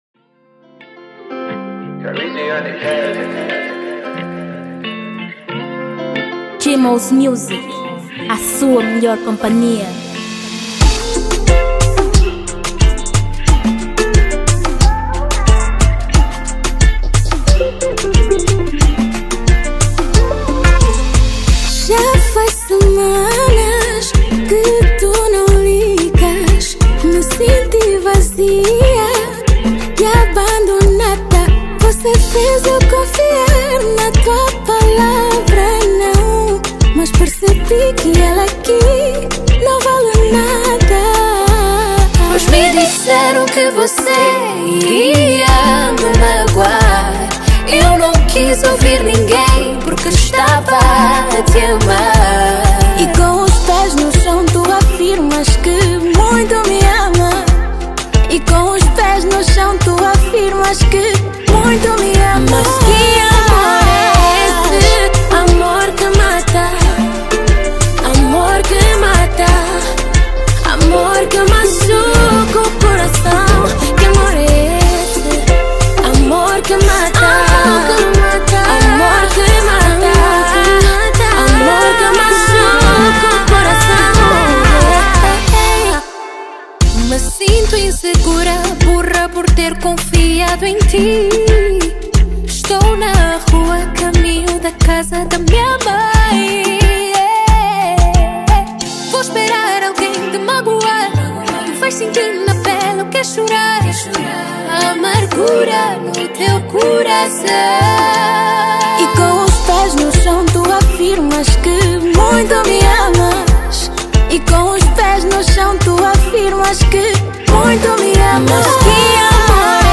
2023 Gênero: Zouk Tamanho